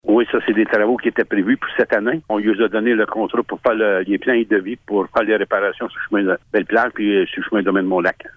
Voici le maire, Laurent Fortin :